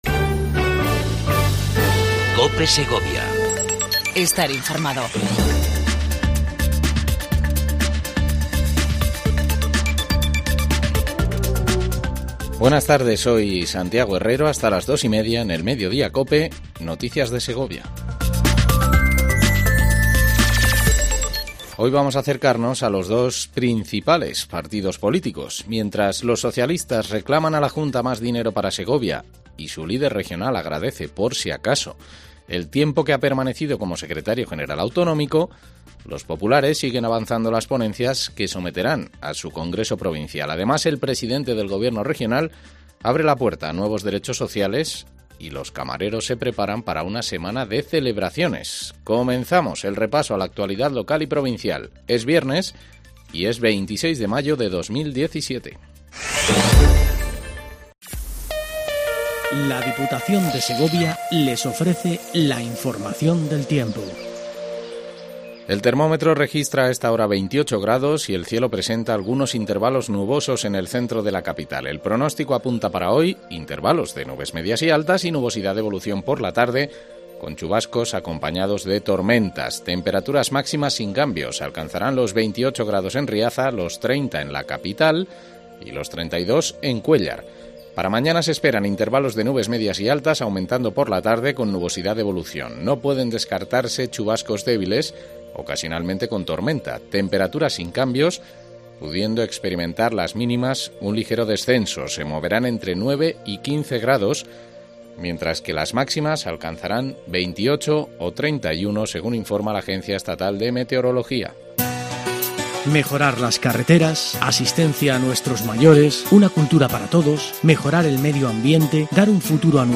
INFORMATIVO MEDIODIA COPE EN SEGOVIA 26 05 17